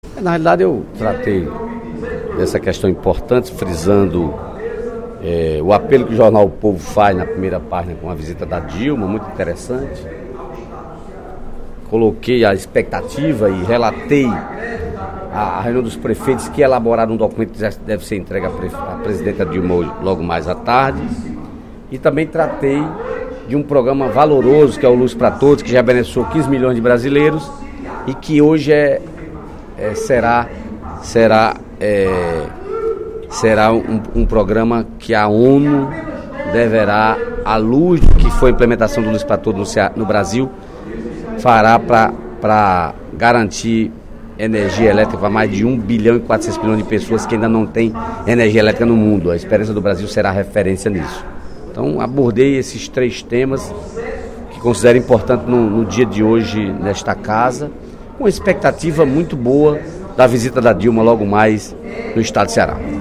A visita da presidente da República, Dilma Rousseff, ao Ceará foi o tema do pronunciamento do deputado Dedé Teixeira (PT) no primeiro expediente da sessão plenária da Assembleia Legislativa desta terça-feira (02/04).